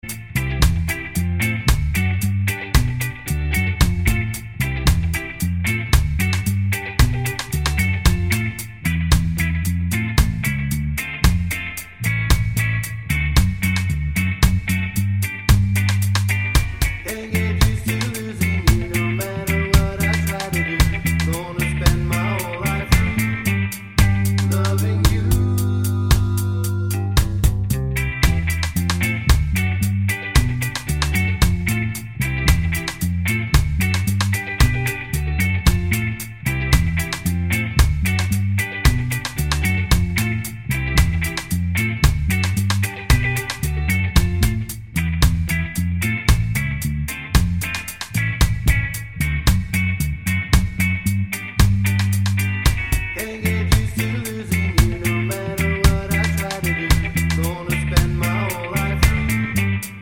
no sax solo Ska 3:02 Buy £1.50